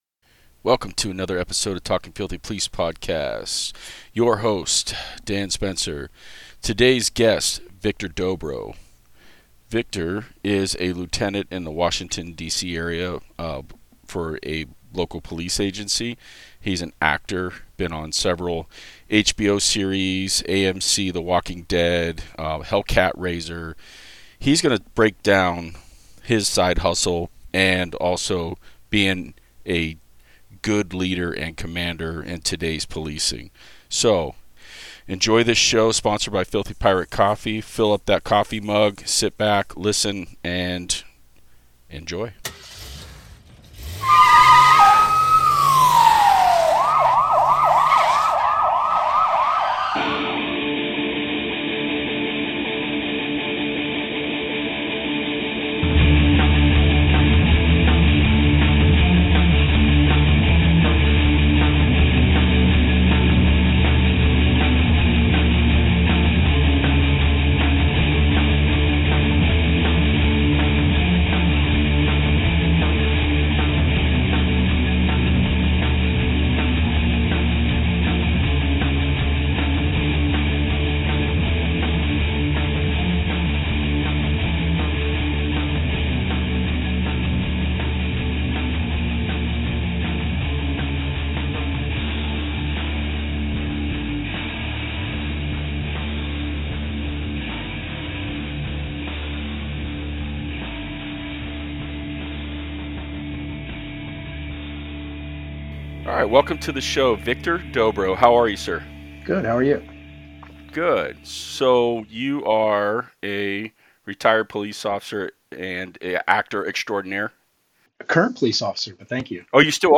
No fancy podcast equipment, just some real talk in hopes to help cops and their mental health.